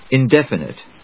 音節in・def・i・nite 発音記号・読み方
/ìndéf(ə)nət(米国英語), ˌɪˈndefʌnʌt(英国英語)/